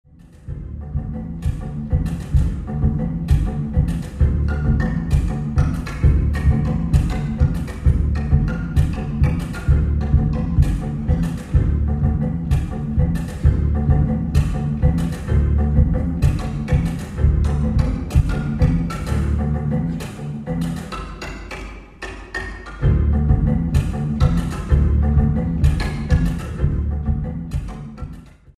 Klavier
Intim das alles und zugleich doch expressiv.